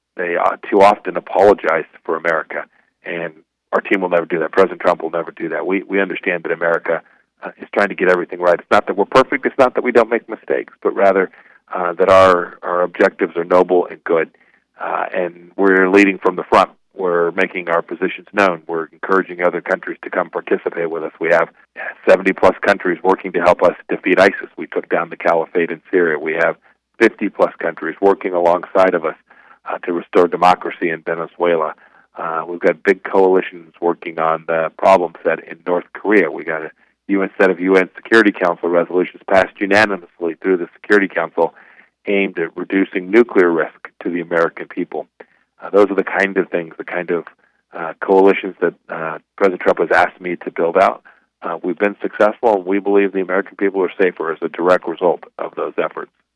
Prior to his Landon Lecture speech Friday, Pompeo joined News Radio KMAN’s morning show and discussed some of his foreign policy achievements since being elevated to the Secretary of State role in April 2018.